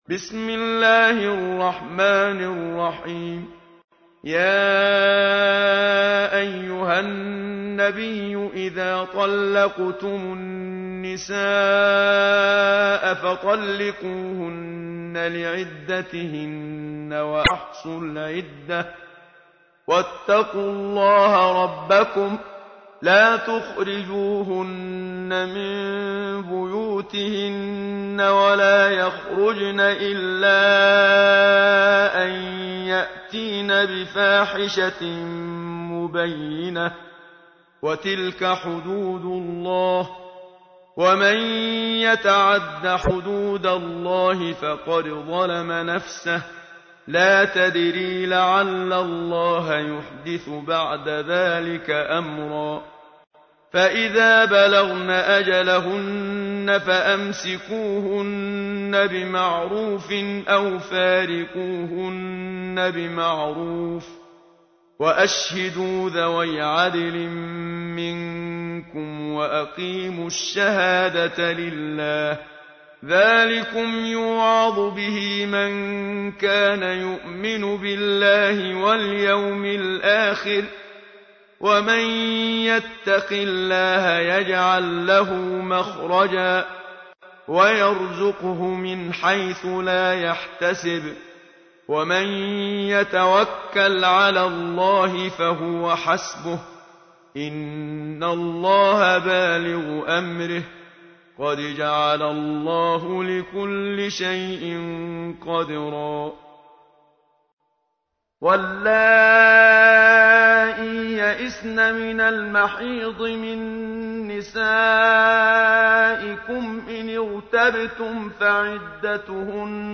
تحميل : 65. سورة الطلاق / القارئ محمد صديق المنشاوي / القرآن الكريم / موقع يا حسين